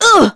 Miruru_L-Vox_Damage_01.wav